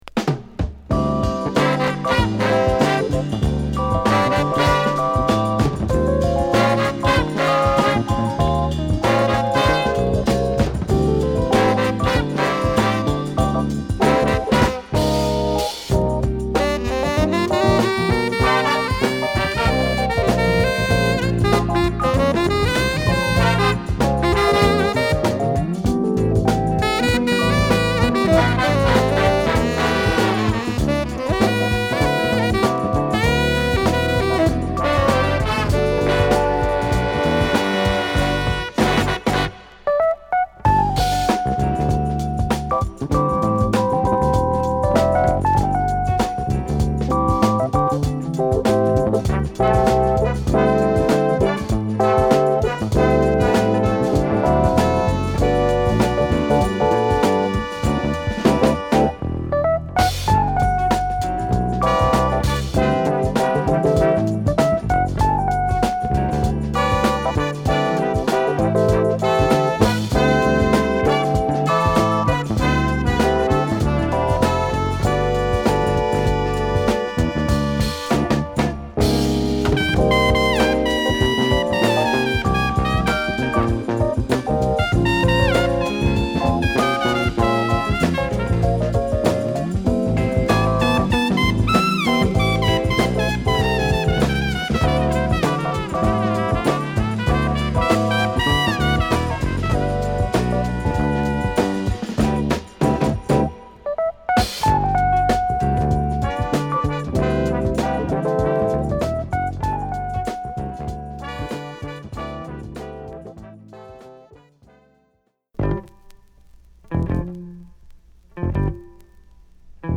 ジャズファンク・クラシック！！